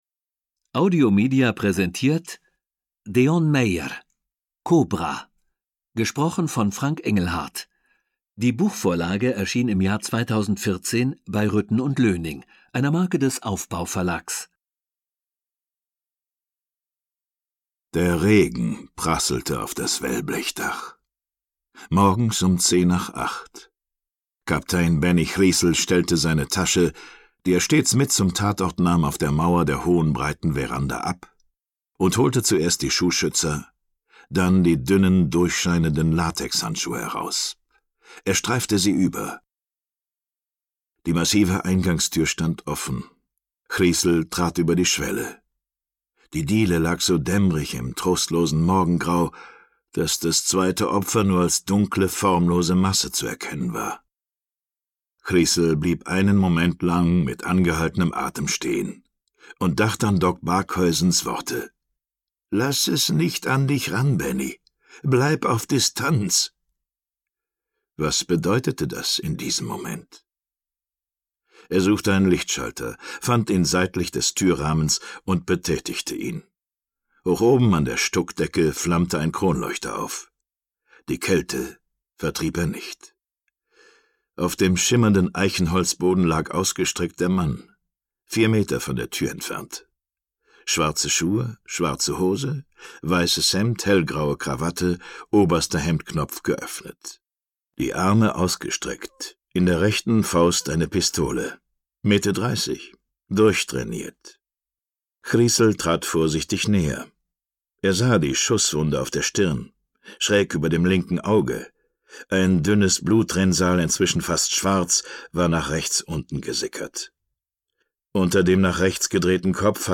Schlagworte Band 4 • Bennie Griessel • Geheimdienst • Hörbuch; Krimis/Thriller-Lesung • Kapstadt • Mord • Südafrika • Südafrika (Region); Krimis/Thriller • Thriller